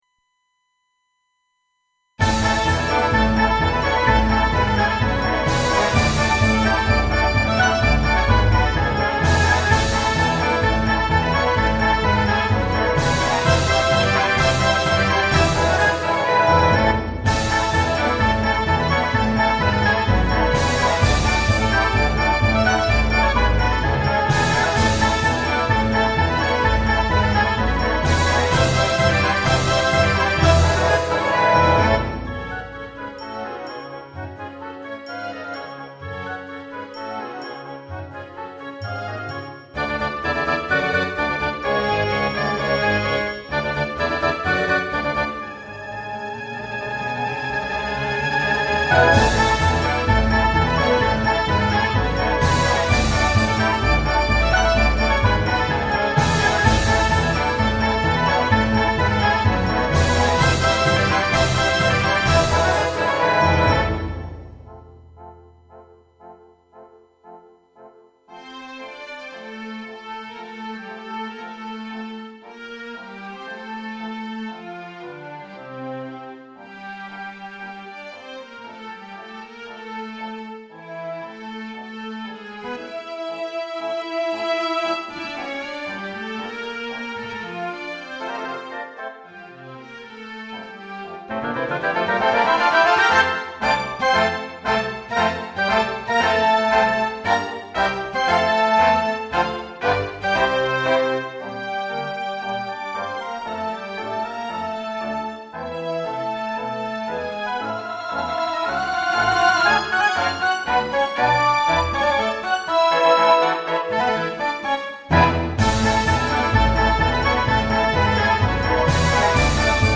SD-90版
賑やかでノリノリな曲ですが、中間部のメロディアスな部分も好きです。